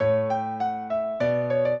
piano
minuet13-7.wav